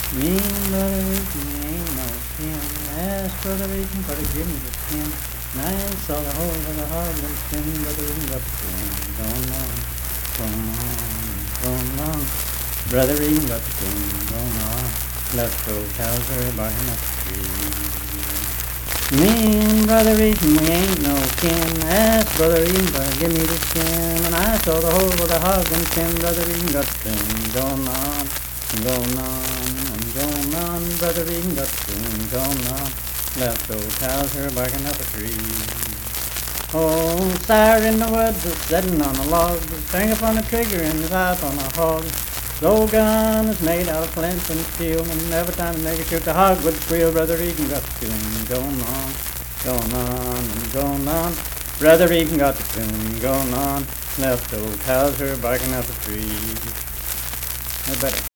Unaccompanied vocal music
Performed in Logan, Ohio.
Minstrel, Blackface, and African-American Songs
Voice (sung)